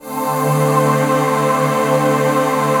DM PAD2-53.wav